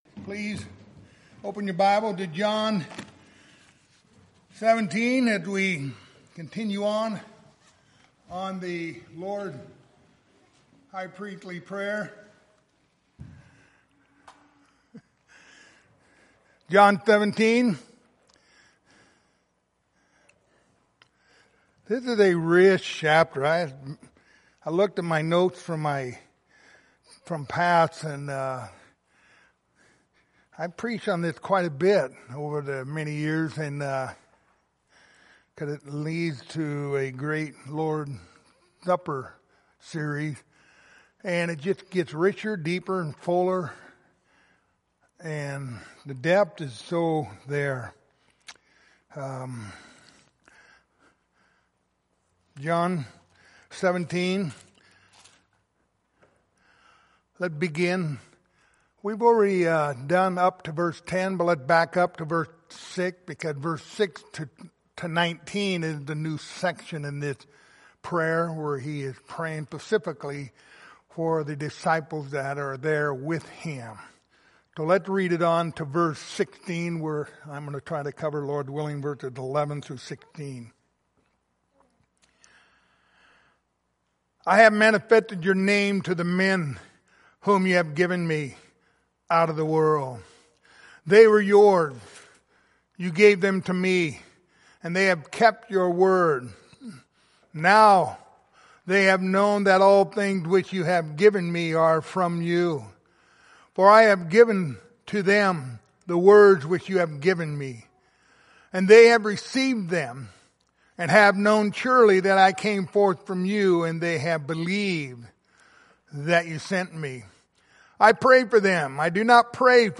Passage: John 17:6-19 Service Type: Wednesday Evening